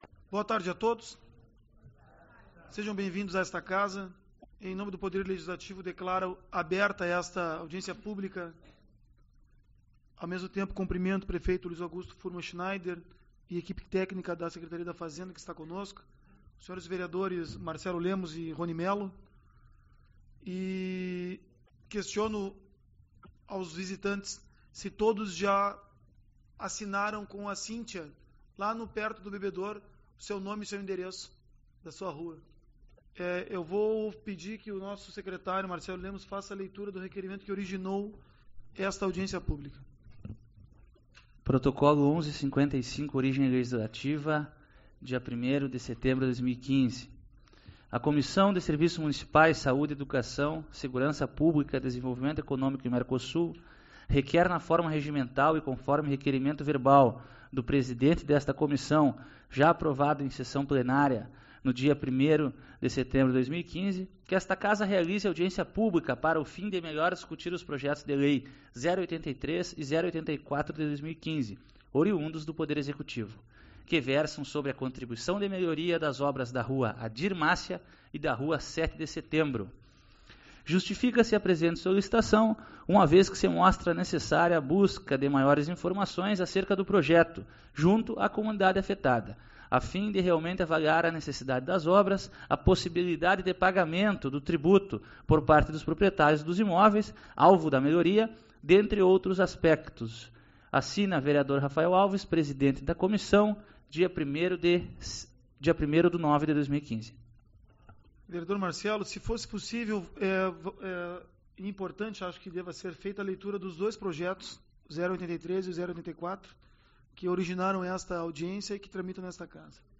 05/08 - Audiência Pública - Proj. Contribuição de Melhoria